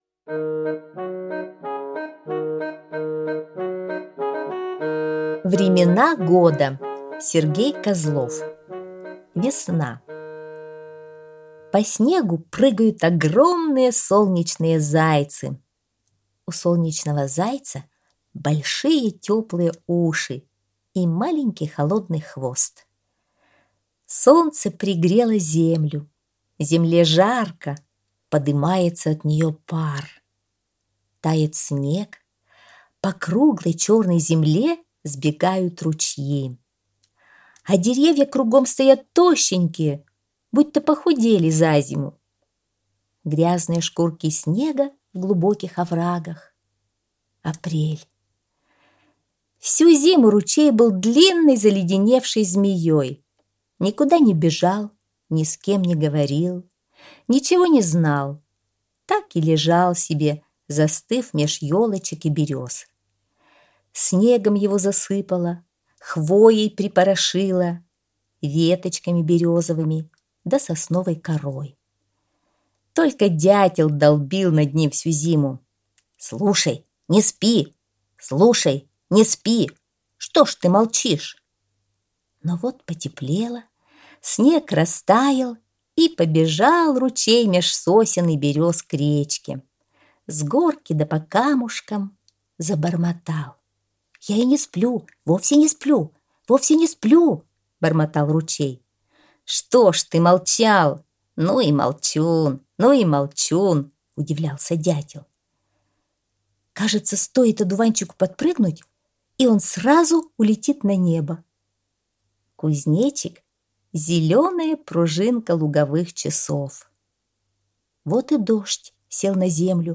Времена года - аудиосказка Сергея Козлова - слушать онлайн